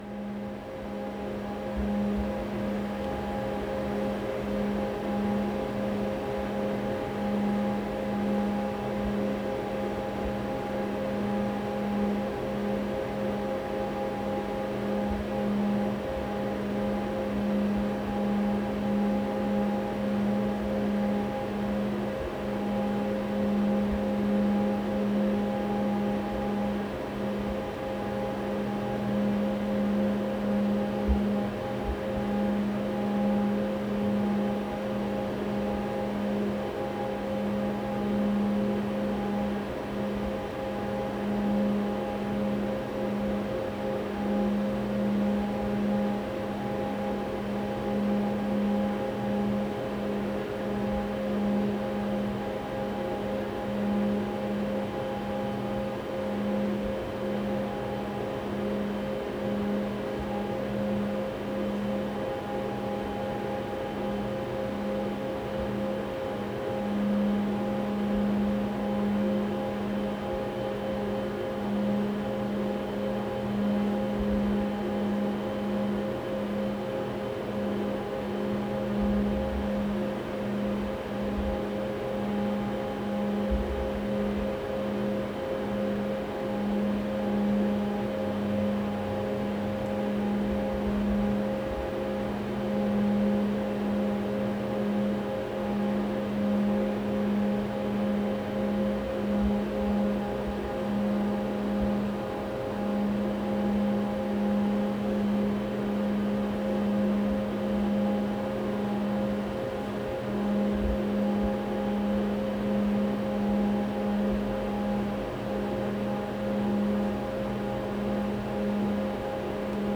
Ambience, Hitech, Machines, Computers, Hiss SND67642.wav